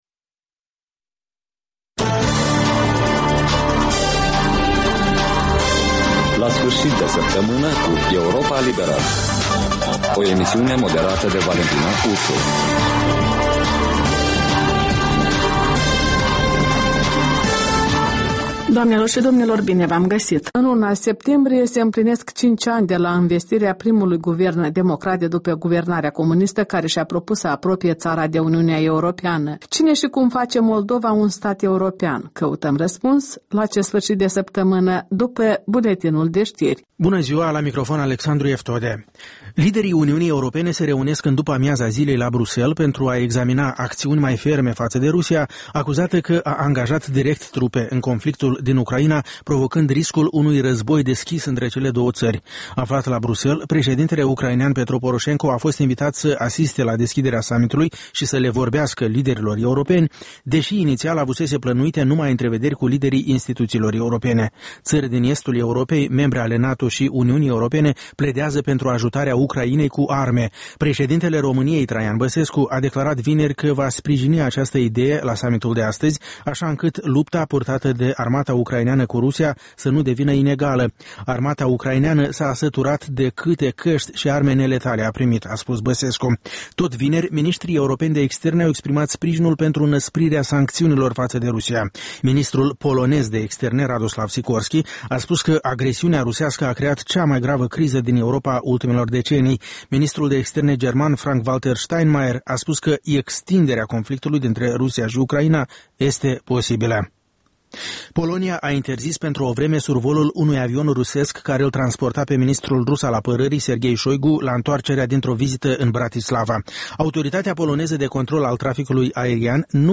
reportaje, interviuri, voci din ţară despre una din temele de actualitate ale săptămînii.